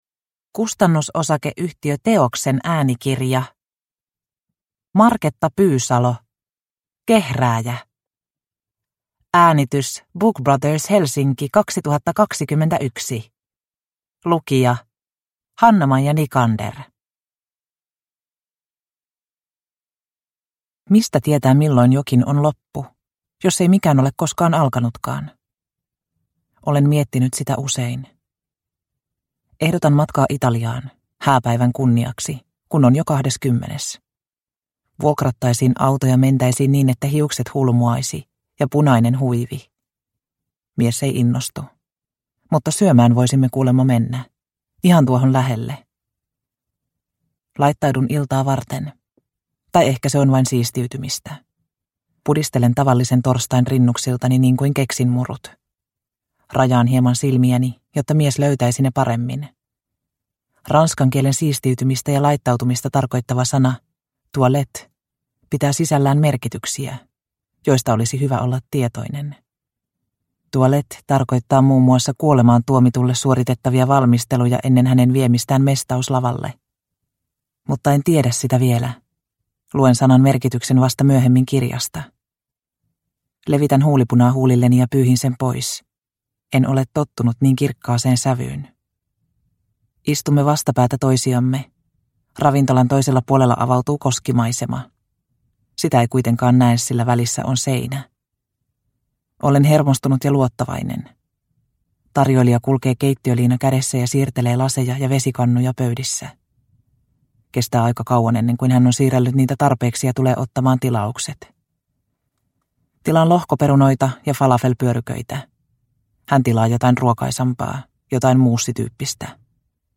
Kehrääjä – Ljudbok – Laddas ner